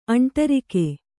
♪ aṇṭarike